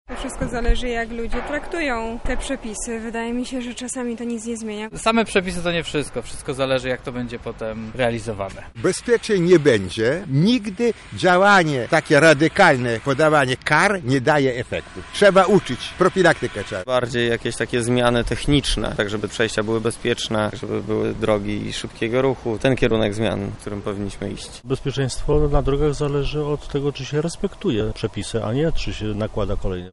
Opinia publiczna jednak nie popiera pomysłu ministerstwa. Lubelscy kierowcy uważają, że mnożenie przepisów i zakazów nie skłoni ludzi do bezpieczniejszej jazdy: